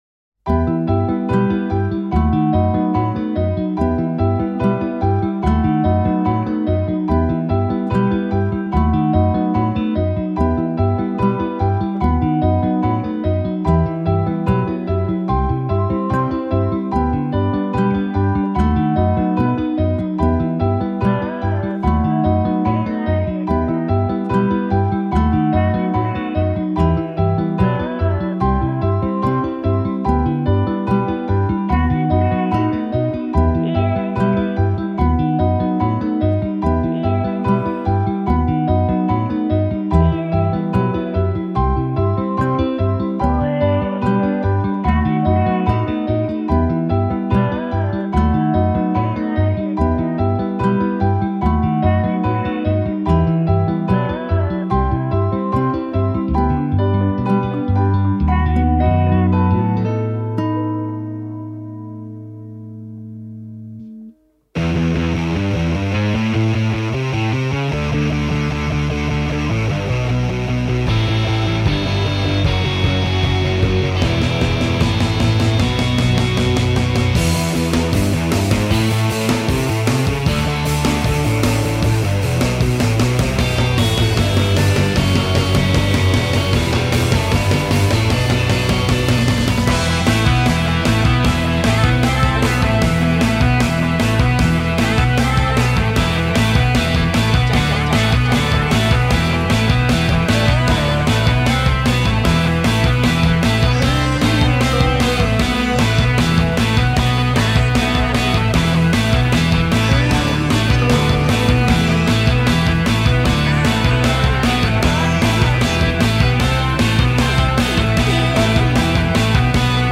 riffs - melodieux - rock - pop